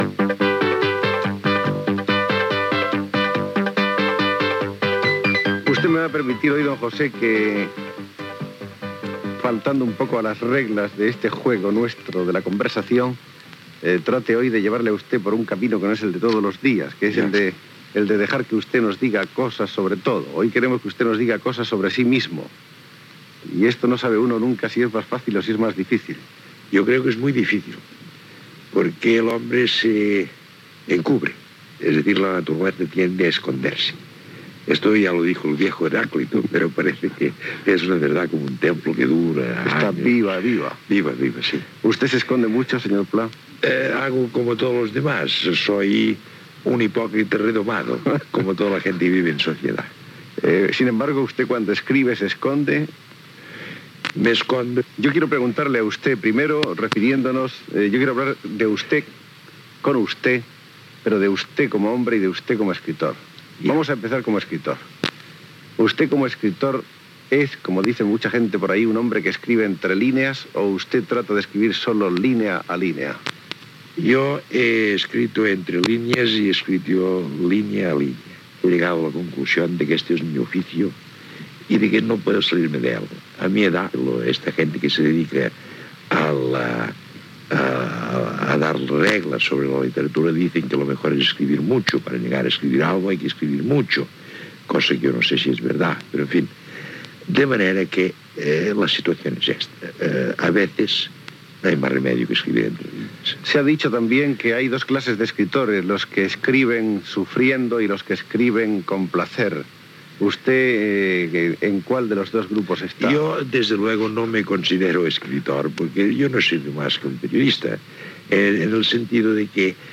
Entrevista a l'escriptor Josep Pla.
Fragment extret del programa "La ràdio que vam sentir", de Ràdio Barcelona, emès l'any 1999.